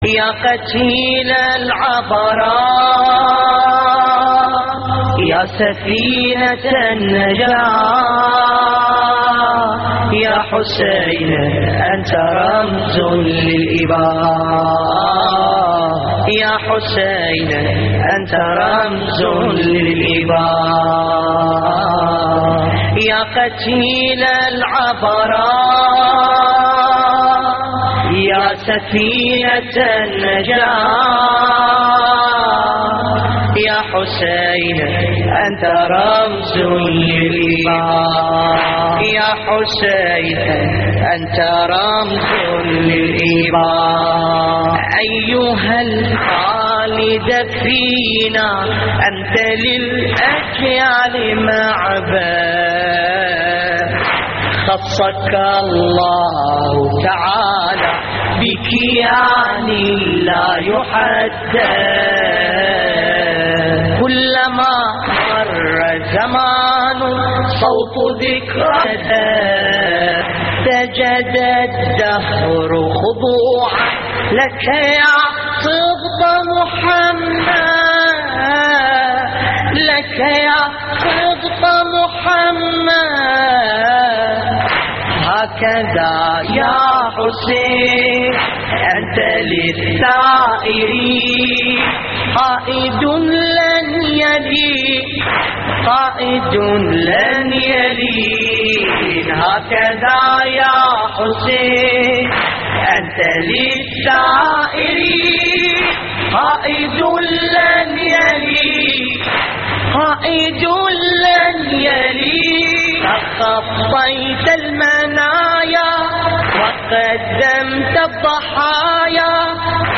الرادود
استديو